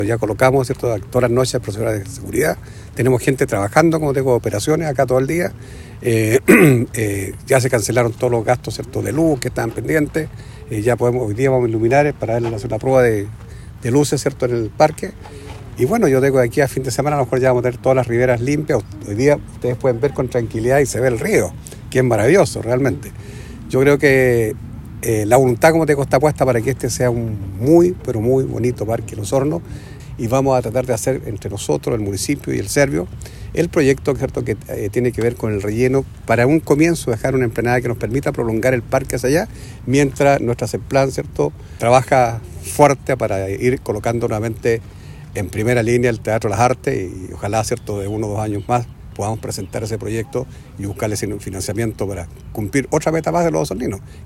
El edil también destacó la reciente prueba de luces realizada para verificar las condiciones técnicas del parque, un paso clave para avanzar en otros proyectos planificados en la zona.